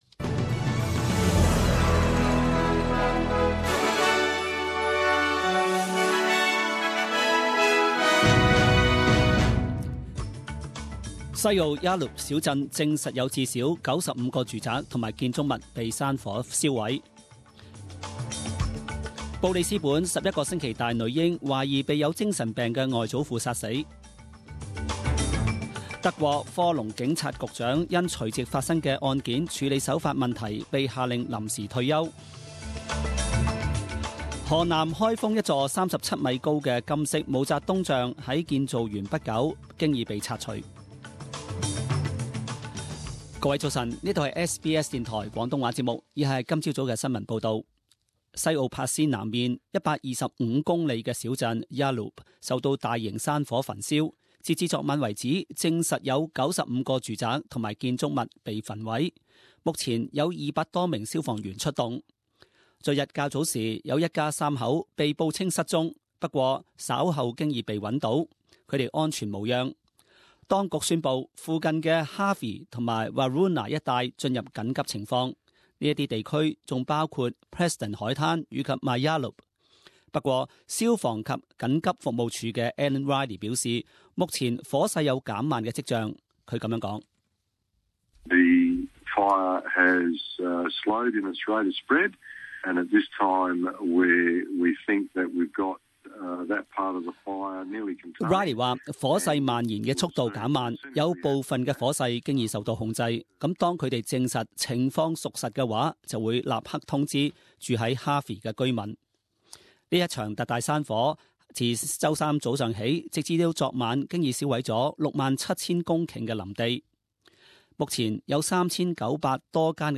十点钟新闻报导 （一月九日）